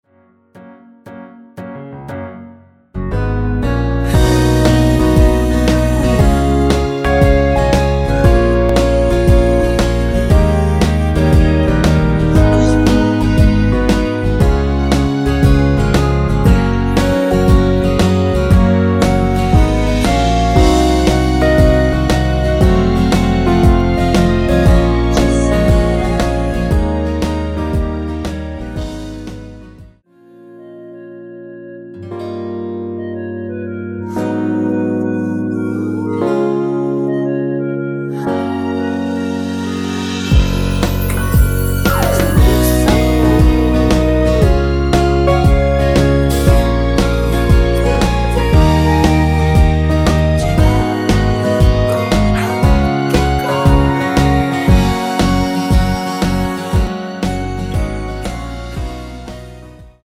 전주 없이 시작하는 곡이라 전주 만들어 놓았습니다.(일반 MR 미리듣기 확인)
원키 멜로디와 코러스 포함된 MR입니다.(미리듣기 확인)
F#
앞부분30초, 뒷부분30초씩 편집해서 올려 드리고 있습니다.